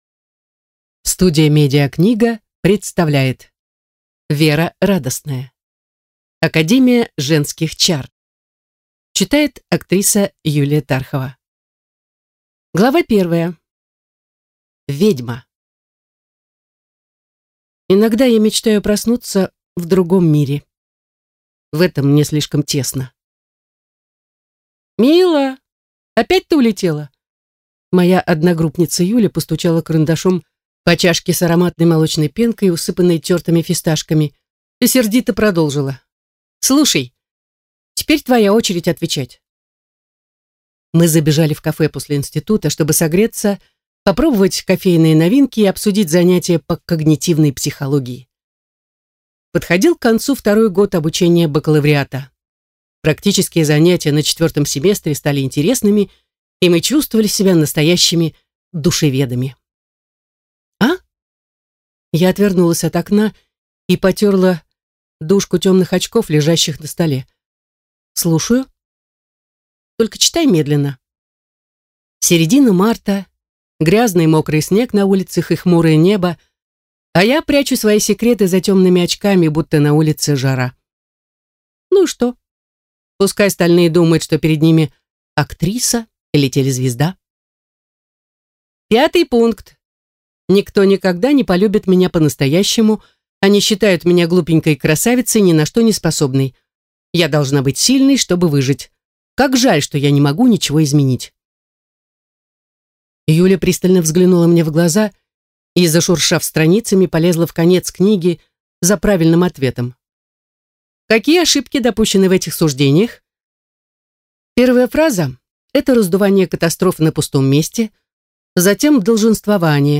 Аудиокнига Академия женских чар | Библиотека аудиокниг